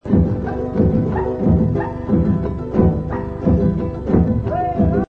AKAI GX 636 umgebaut für Magnettonwiedergabe
Aus unserem Filmfundus haben wir eine 120 Meter Super8 Filmspule mit Stereoton von Weltreiseaufnahmen aus den 70er Jahren herausgesucht und 12 kurze Samples zum kurzen Anspielen für Sie angefertigt: